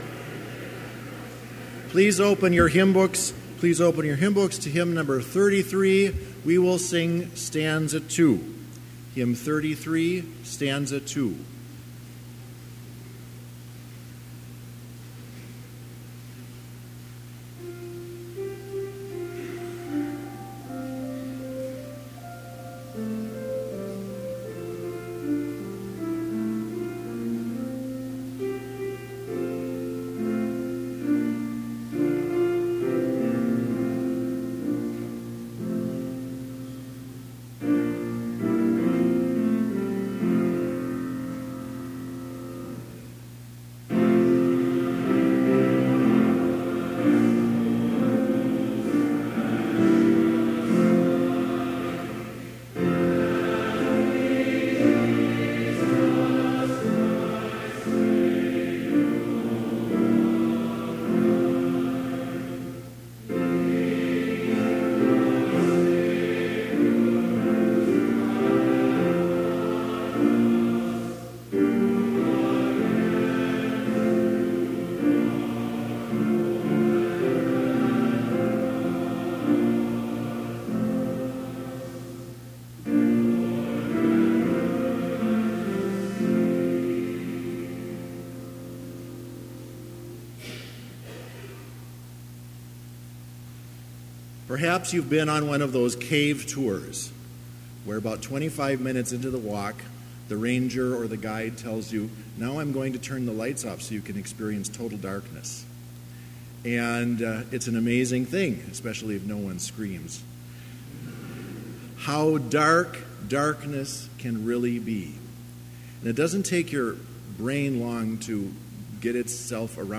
Chapel worship service in BLC's Trinity Chapel
Complete service audio for Chapel - January 25, 2018